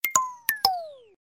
LowBattery.ogg